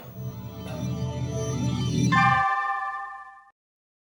La source sonore n'est non seulement pas visible mais est un élément ajouté qui ne fait pas partie de l'action. Son but est de créer ou de souligner une ambiance, permettant au spectateur de mieux percevoir le message.